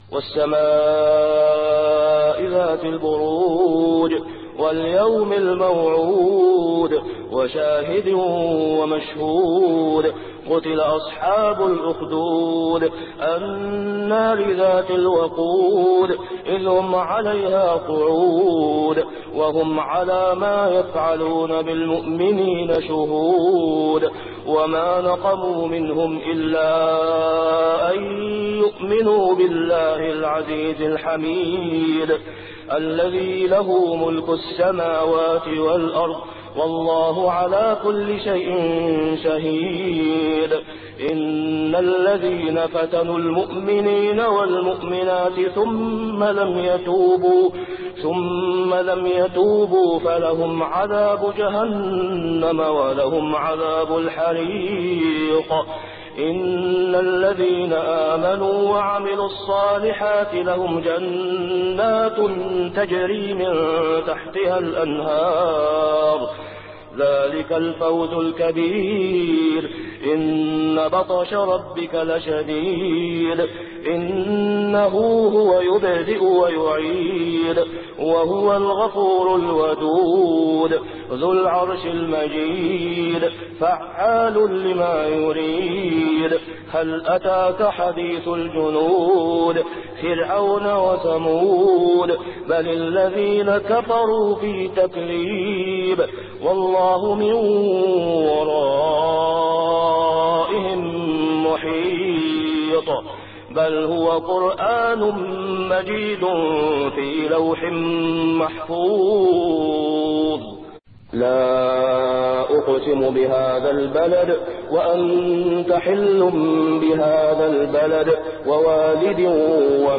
صلاة الفجر عام 1423هـ | سورتي البروج و البلد كاملة | > 1423 🕋 > الفروض - تلاوات الحرمين